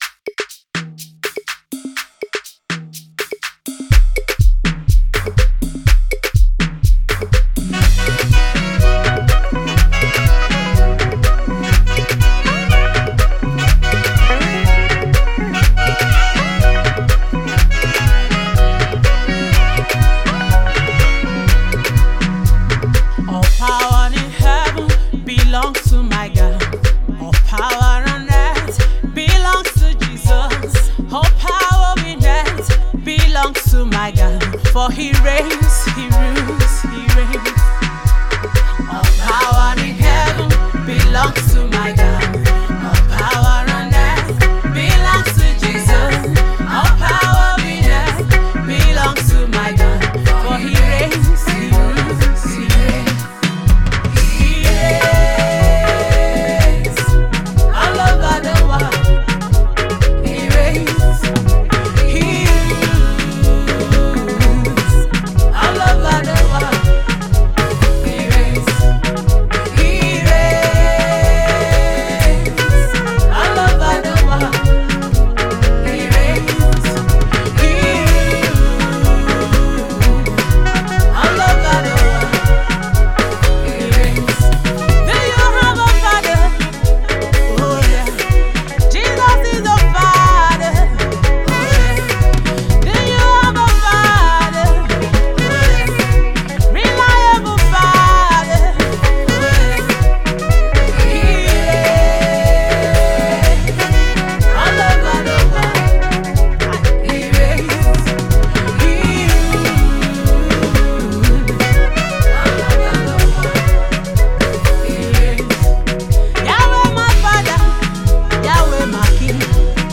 Gospel
a song of praise to God almighty